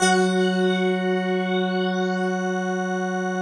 CHROMA F#4.wav